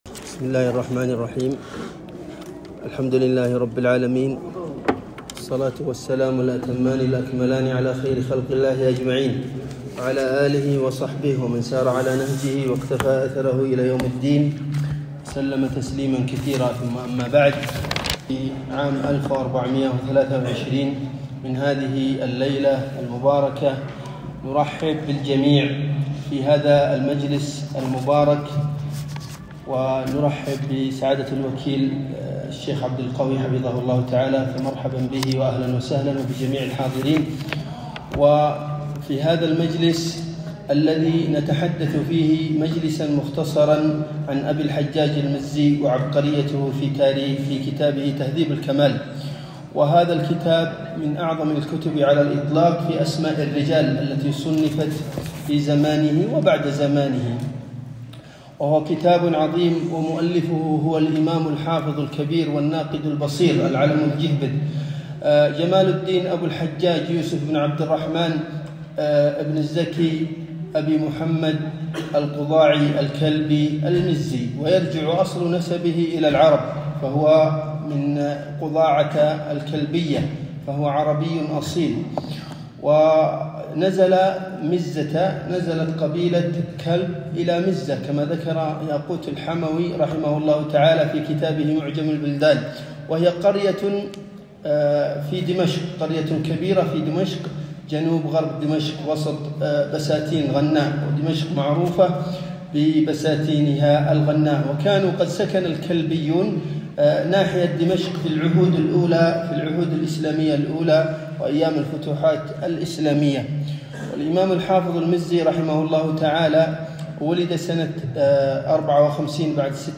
محاضرة - الإمام أبو الحجاج المزي وعبقريته في كتابه تهذيب الكمال في أسماء الرجال